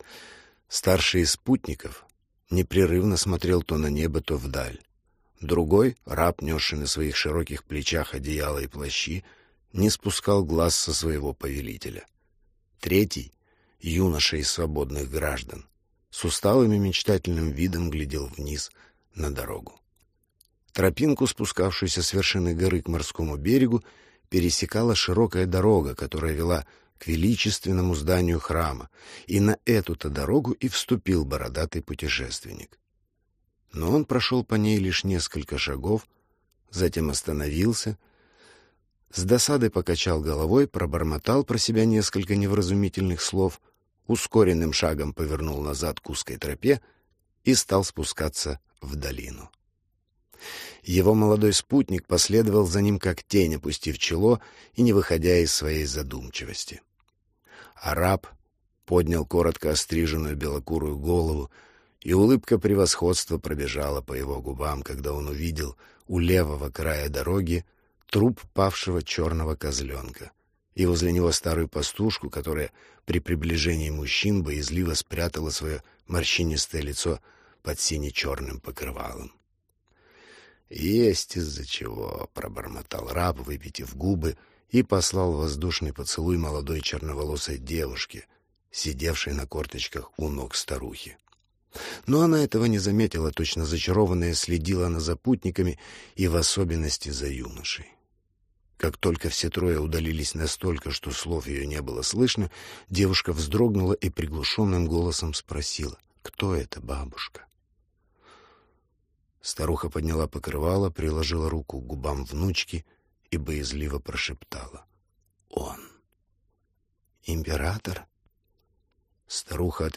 Аудиокнига Император | Библиотека аудиокниг